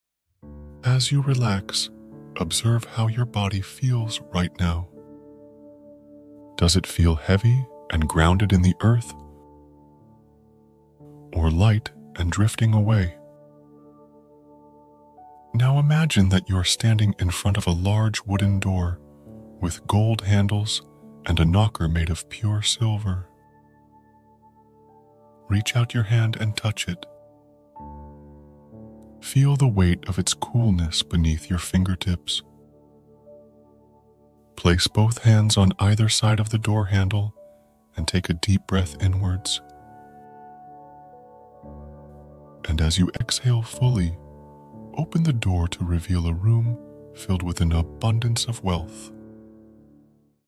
Indulge in a unique journey with each meditation, expertly narrated and accompanied by soothing background music tailored for an immersive experience.
Professionally narrated for maximum impact.
Unique background music tailored to each meditation.